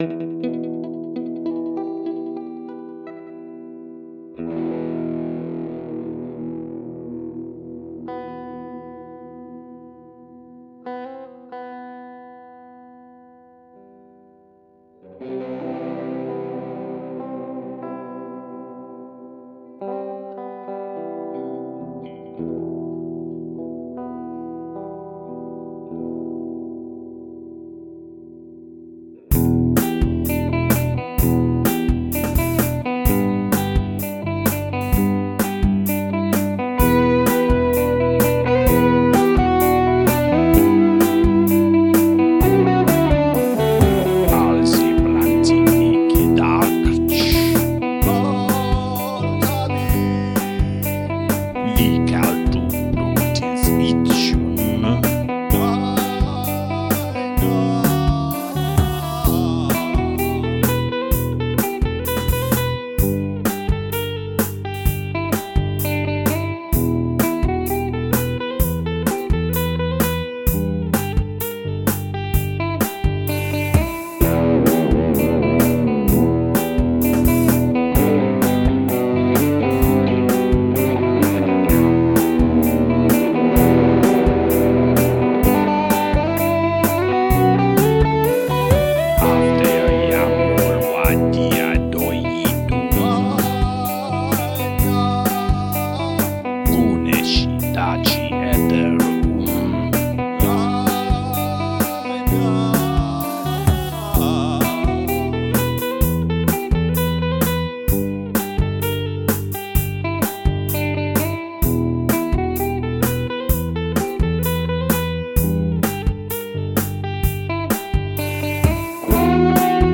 The chord sequence is exotic, the guitar solo